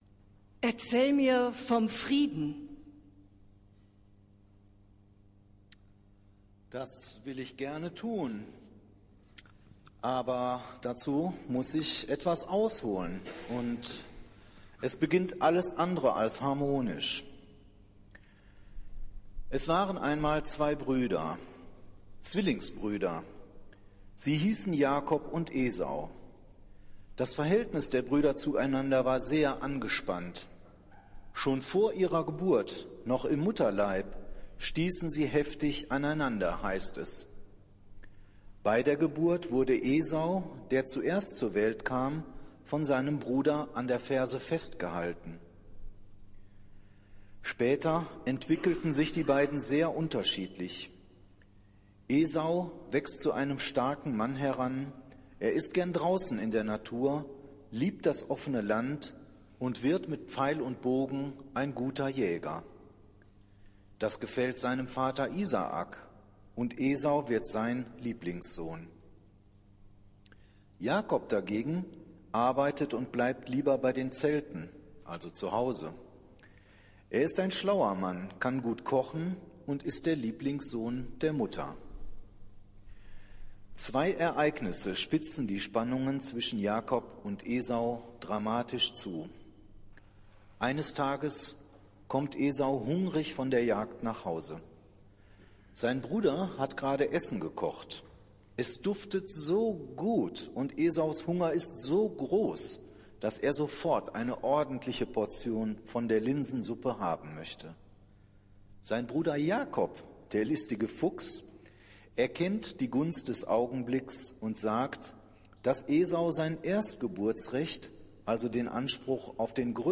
Predigt des Gottesdienstes aus der Zionskirche zum Buß- und Bettag am Mittwoch, den 20. November 2024
Wir haben uns daher in Absprache mit der Zionskirche entschlossen, die Predigten zum Nachhören anzubieten.